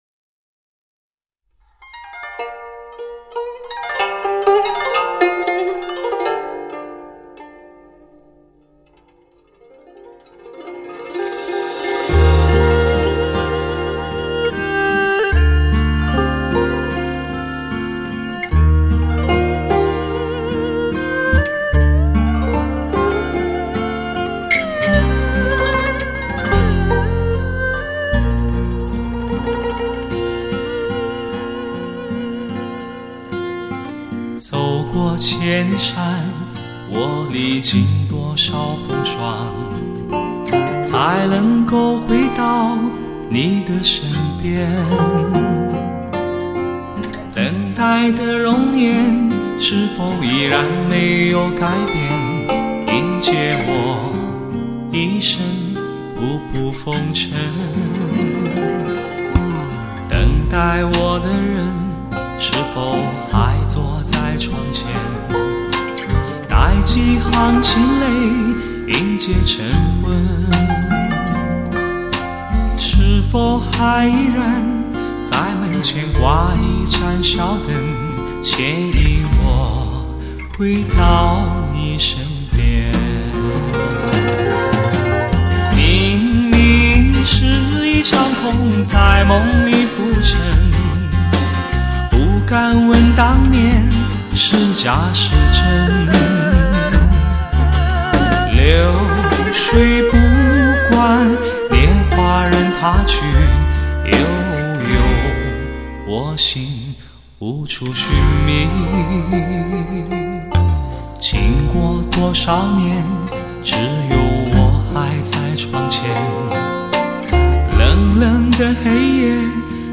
类型: HIFI试音
“被上帝亲吻过”的最完美人声，顶级的DTS-ES声效制作，成就最完美的人声精选。